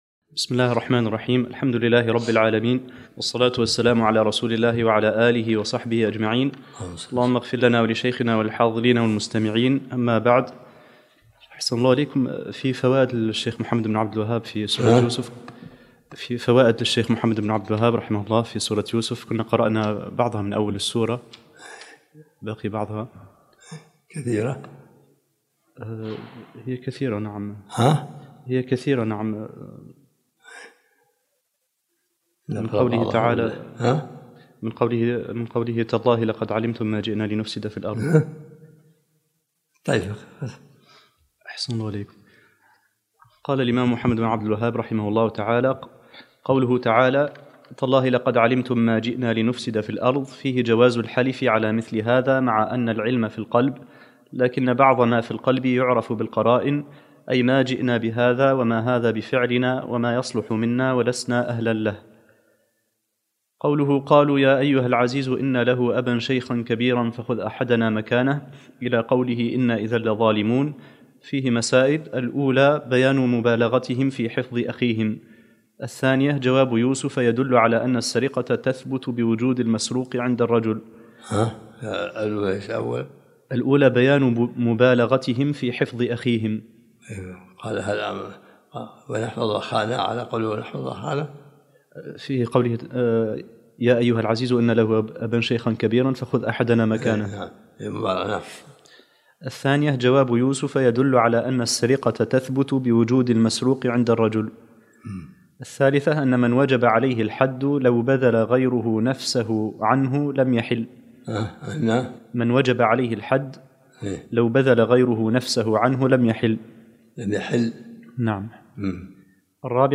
الدرس العشرون من سورة يوسف